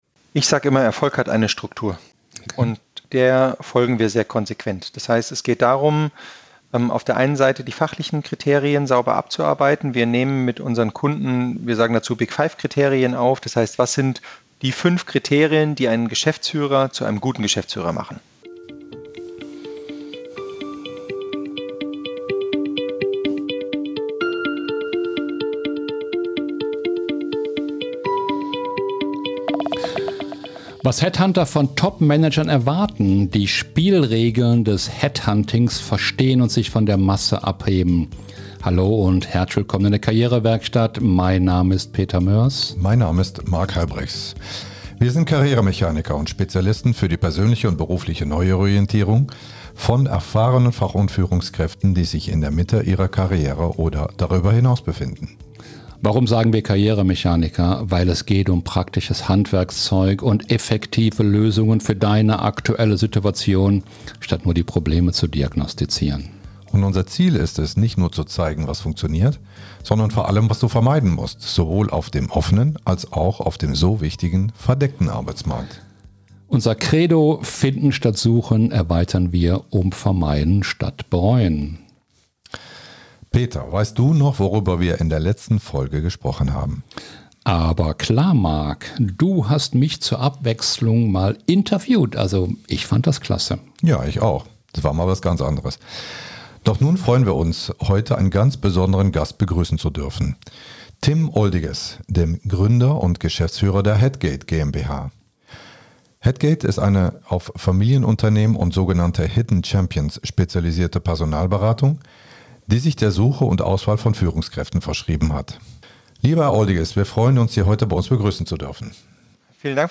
Beschreibung vor 5 Monaten Der Jobmarkt für Führungskräfte ist ineffizient, aber voller Chancen. Im Gespräch